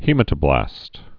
(hēmə-tə-blăst, hĭ-mătə-)